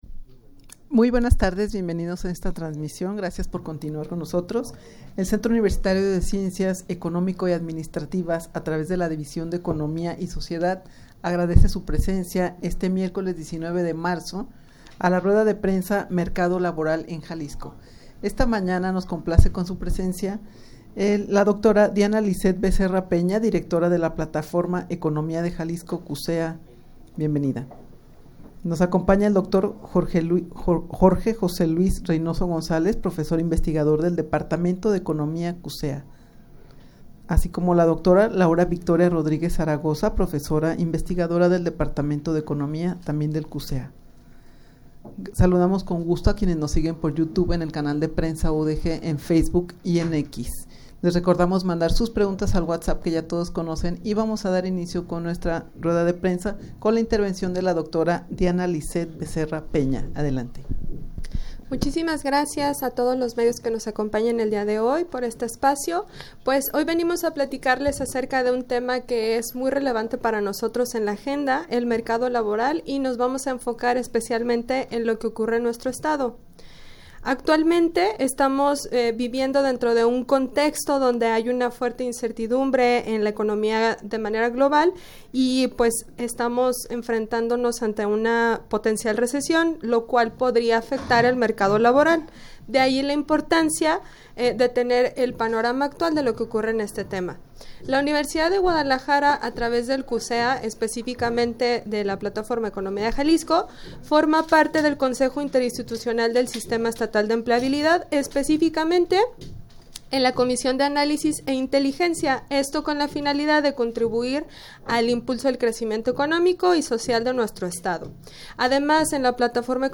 Audio de la Rueda de Prensa
rueda-de-prensa-mercado-laboral-en-jalisco.mp3